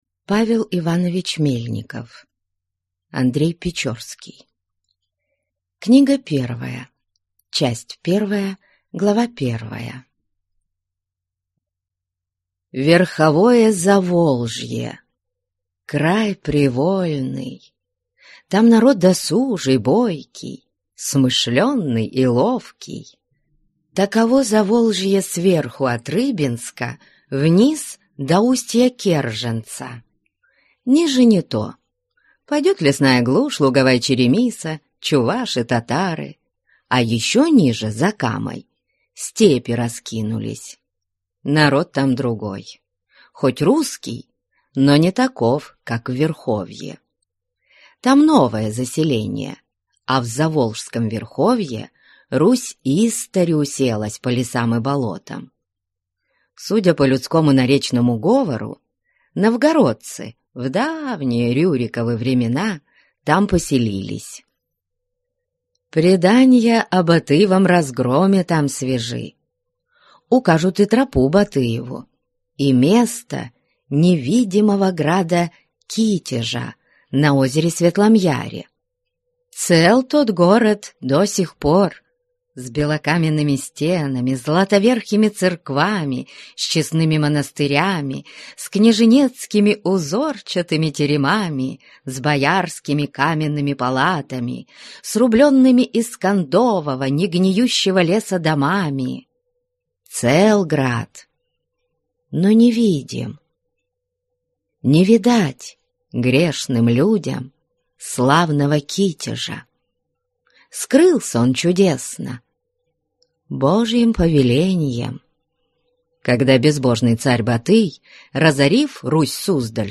Аудиокнига В лесах (часть первая) | Библиотека аудиокниг